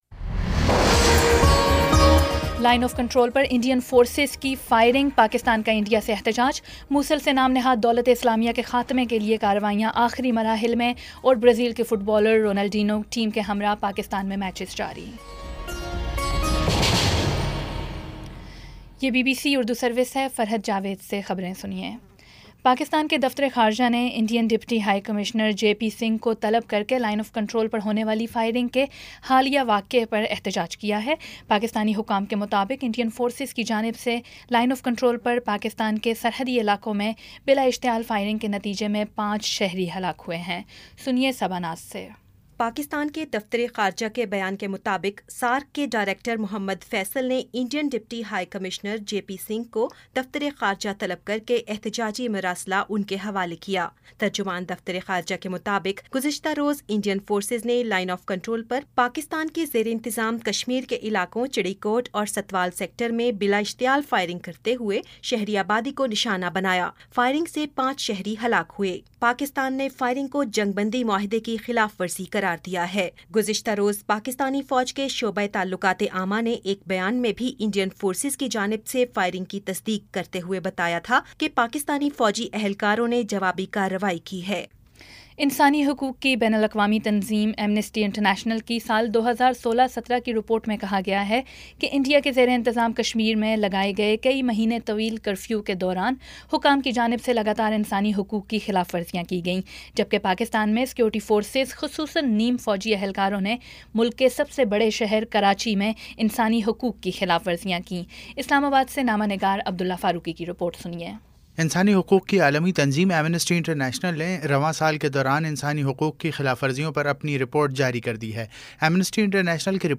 جولائی 09 : شام پانچ بجے کا نیوز بُلیٹن